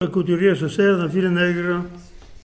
Nieul-sur-l'Autise
Catégorie Locution